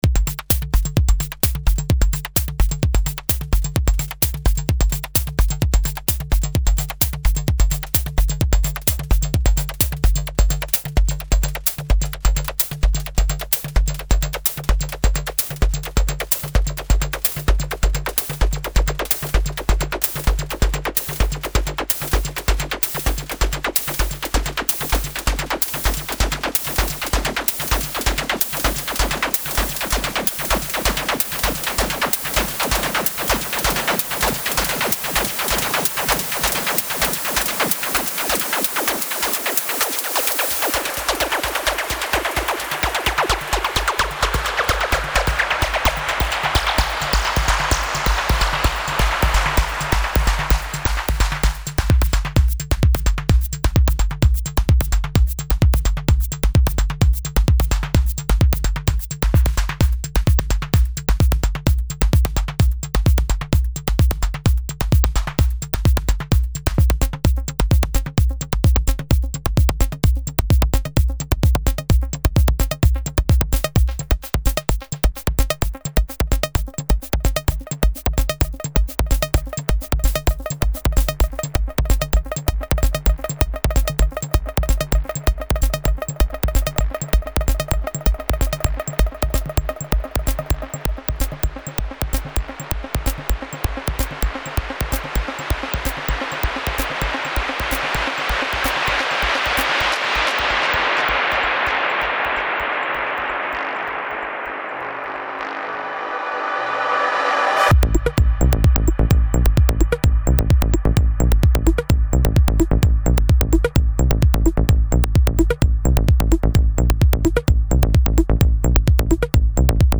Style: Techno